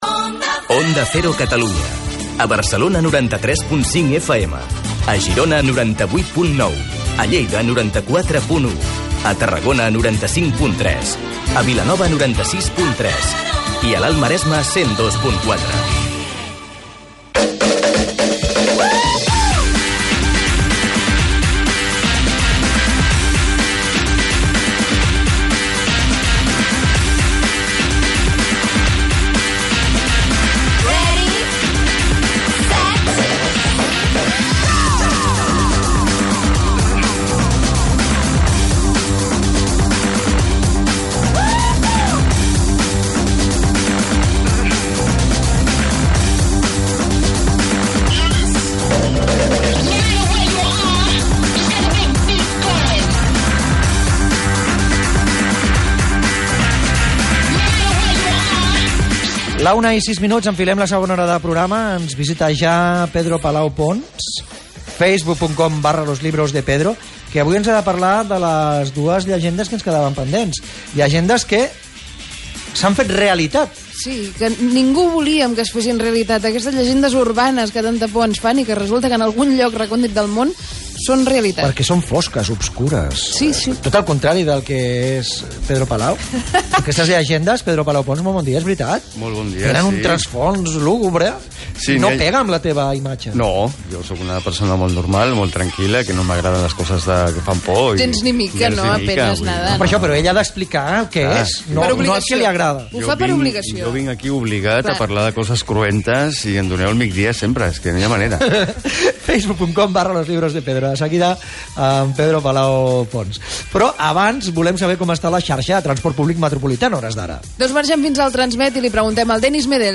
Mi intervención abarca del minuto 41:00 al 47:00